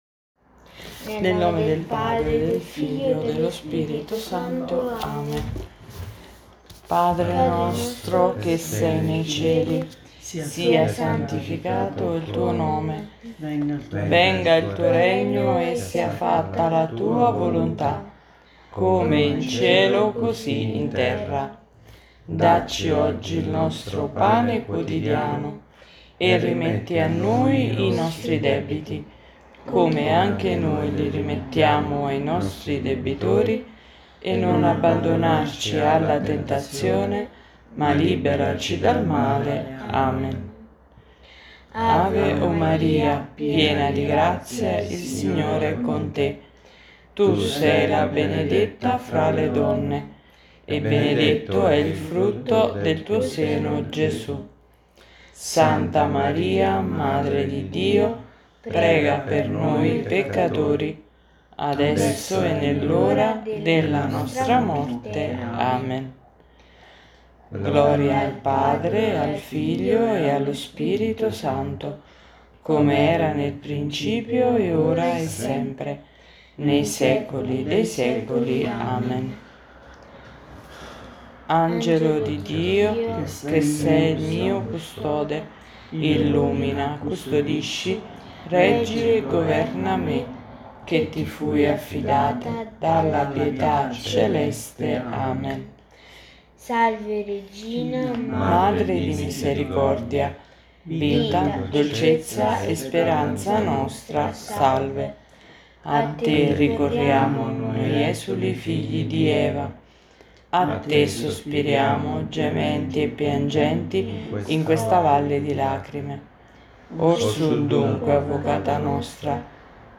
Oggi pomeriggio, dopo essere stati a casetta tua, ci siamo incontrati online per la preghiera domenicale.